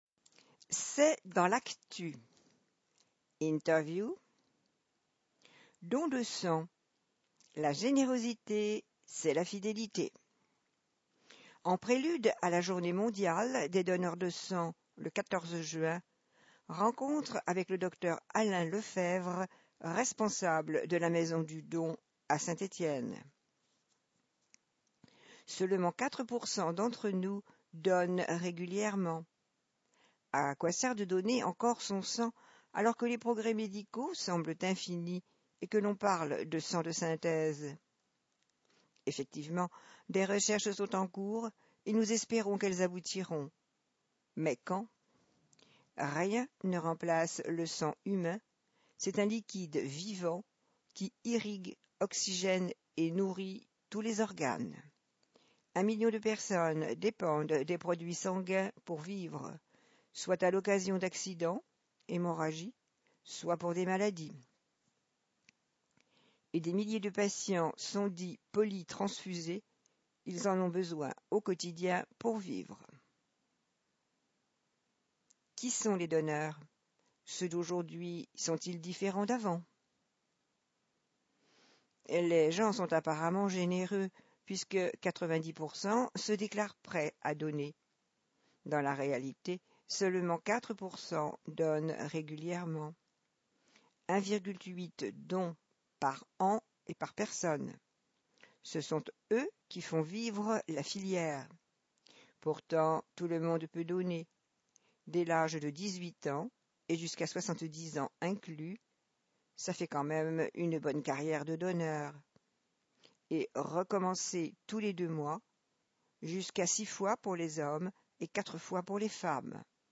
Loire Magazine n°156 version sonore
07-cest_dans_lactu_interview.mp3